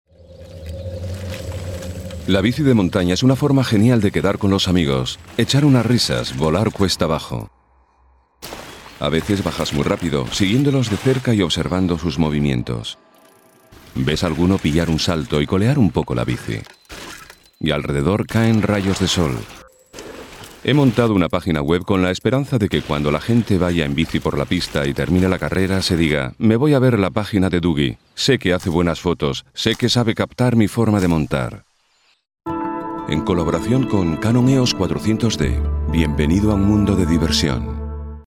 Sprechprobe: Werbung (Muttersprache):
European Spanish male voice-over, huge experience in corporate DVDs, e-learning and audiobooks.